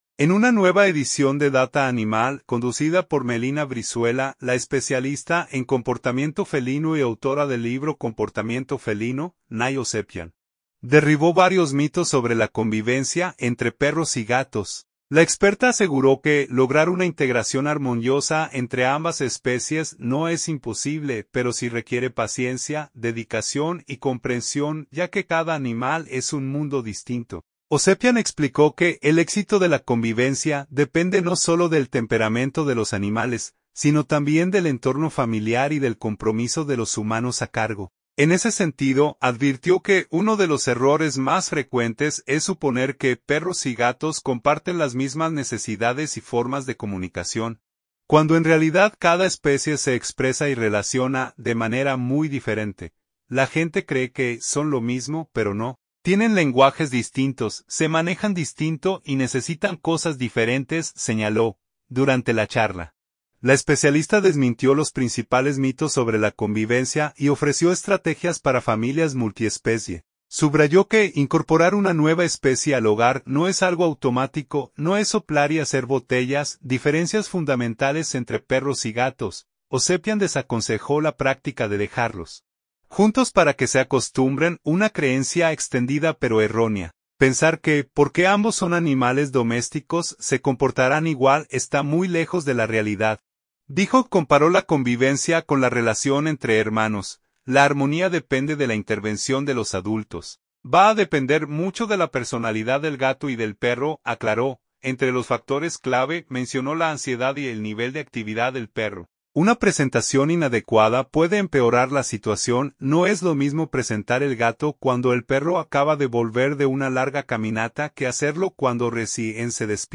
Durante la charla, la especialista desmintió los principales mitos sobre la convivencia y ofreció estrategias para familias multiespecie.